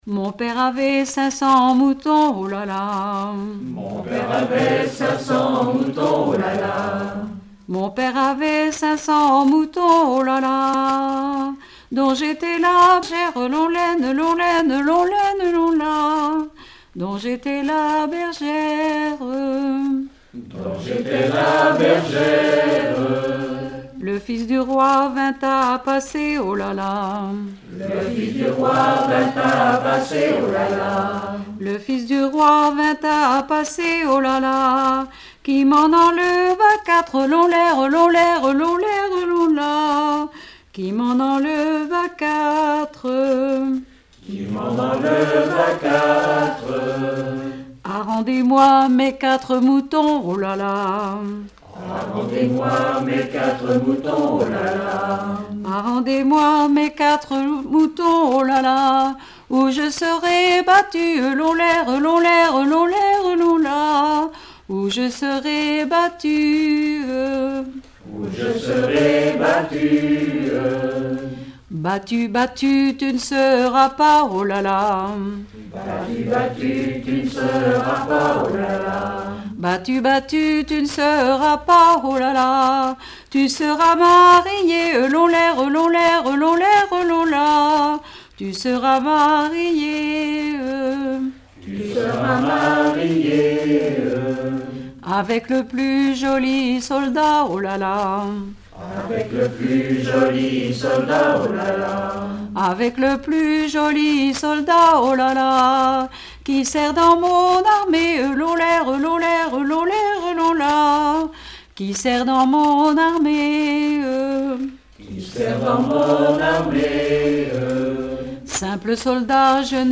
Genre laisse
Collectif de chanteurs du canton - veillée (2ème prise de son)
Pièce musicale inédite